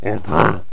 mummy_ack2.wav